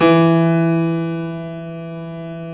Index of /90_sSampleCDs/InVision Interactive - Lightware VOL-1 - Instruments & Percussions/GRAND PIANO1